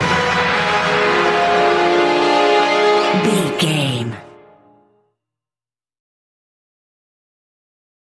In-crescendo
Thriller
Aeolian/Minor
E♭
tension
ominous
dark
haunting
eerie
orchestra
string
brass
horror
Horror Synths
atmospheres